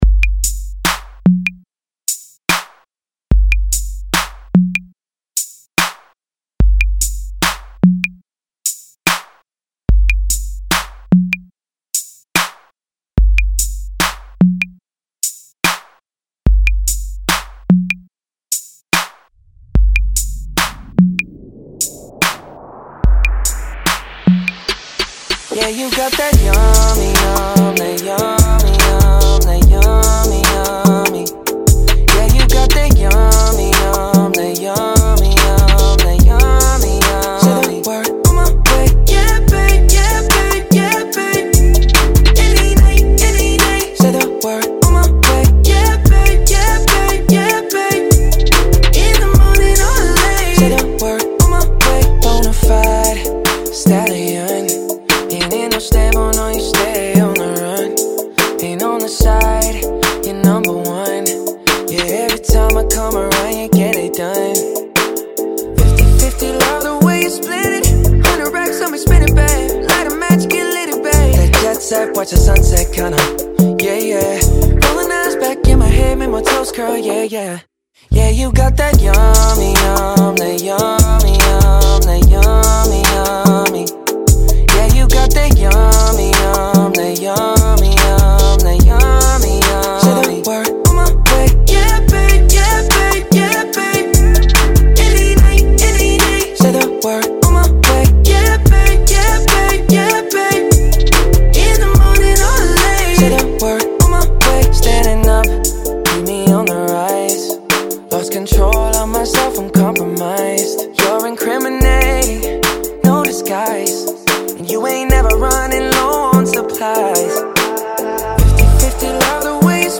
73 Bpm
Funky